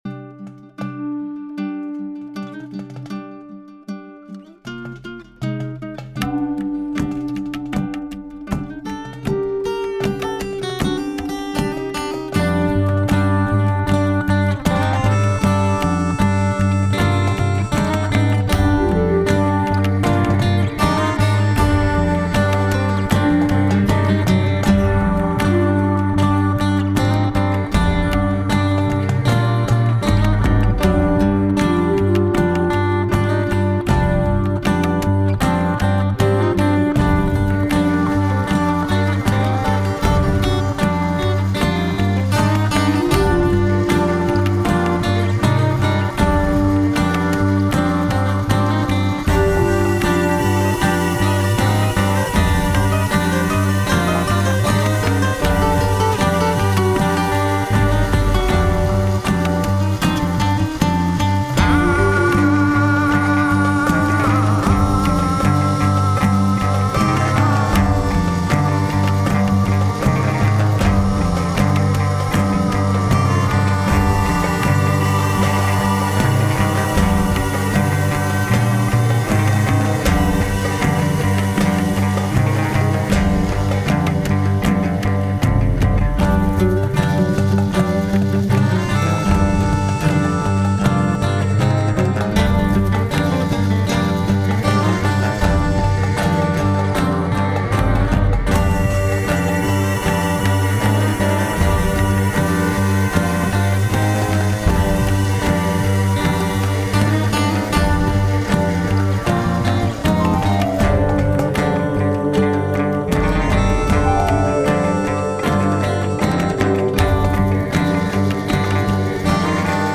le "viole" c'est une flûte spécial sur la quelle j'ai rajouté des effets
guitare/bass, tabla, mélodica et flûte Sard
puis une deuxième partie guitare enregistré à part avec un autre guitariste pour les parties solo quand la structure du morceau fût terminé...